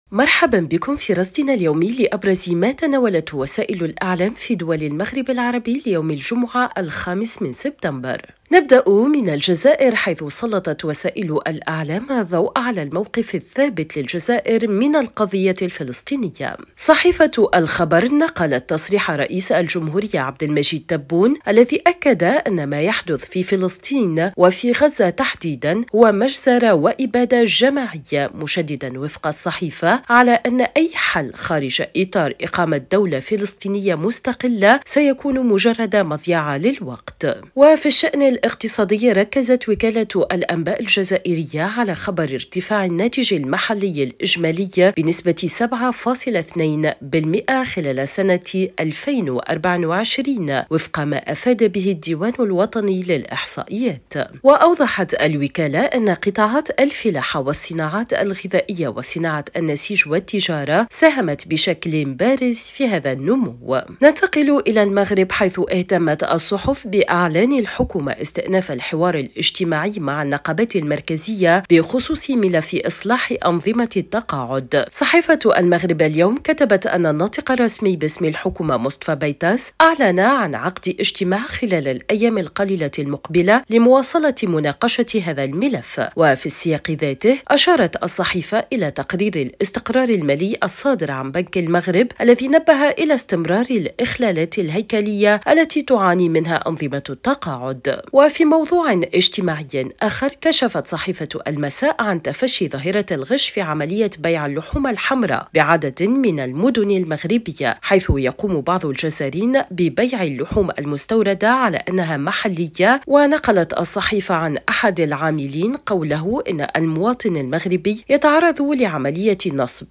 صدى المغرب برنامج إذاعي يومي يُبث عبر إذاعة الشرق، يسلّط الضوء على أبرز ما تناولته وسائل الإعلام في دول المغرب العربي، بما في ذلك الصحف، القنوات التلفزية، والميديا الرقمية.